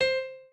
pianoadrib1_22.ogg